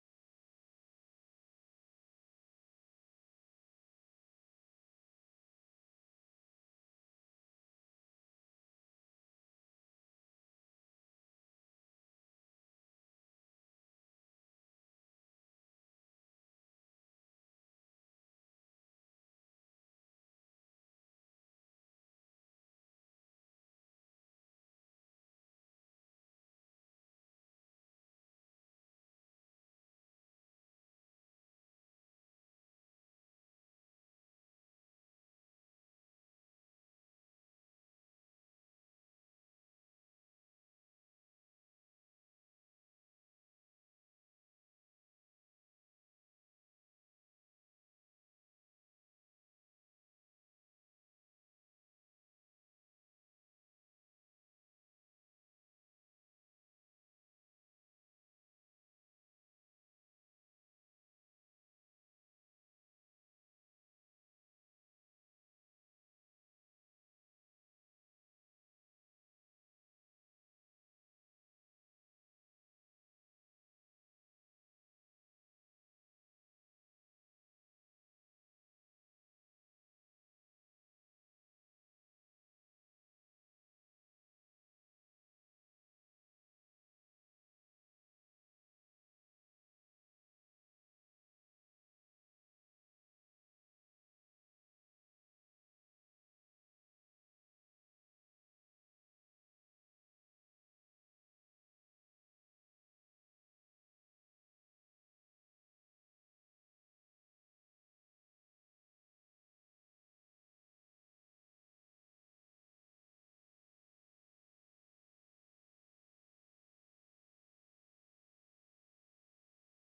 NPC_Scene06_Crowd_BiSheng.ogg